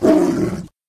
tiger
attack2.ogg